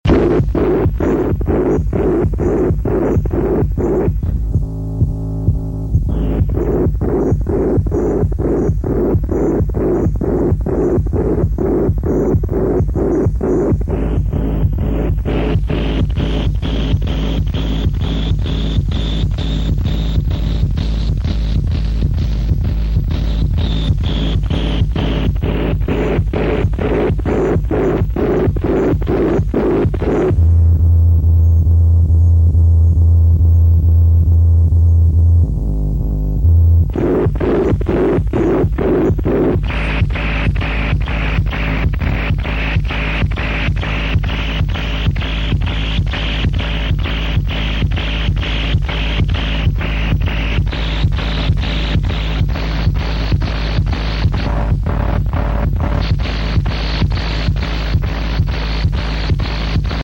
industrial/noise